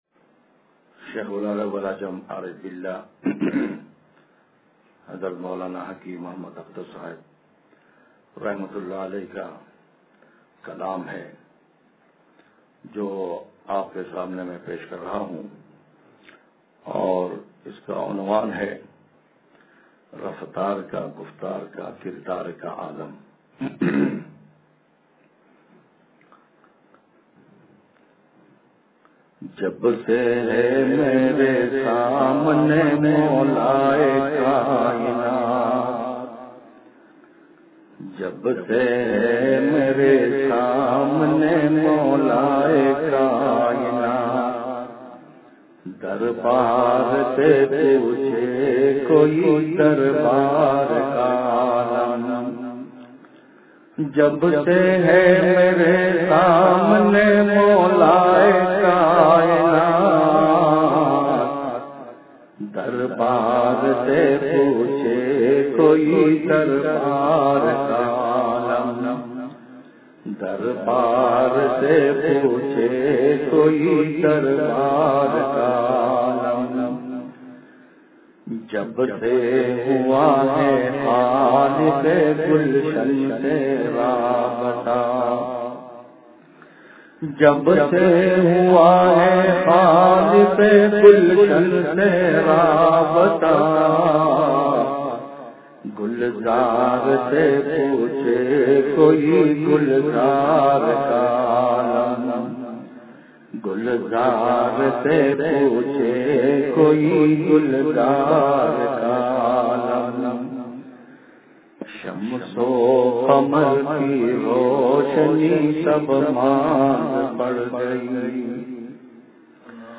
رفتار کا گفتار کا کردار کا عالم – اتوار بیان